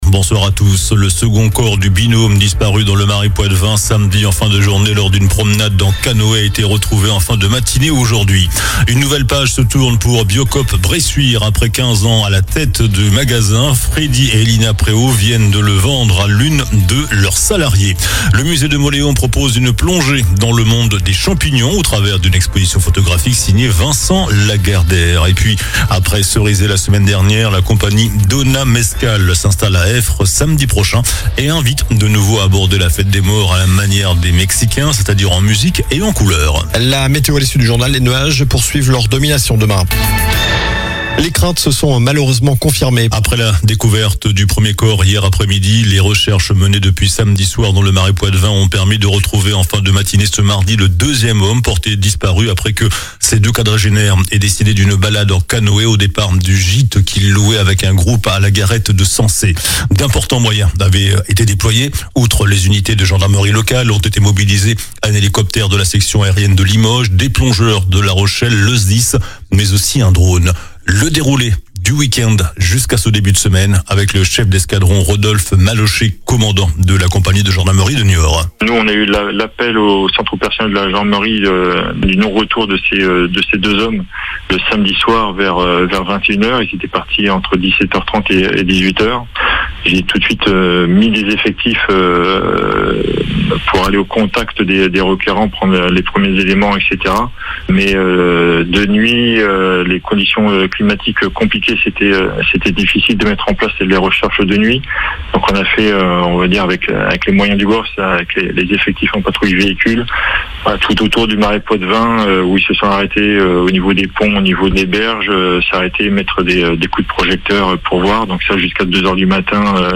JOURNAL DU MARDI 28 OCTOBRE ( SOIR )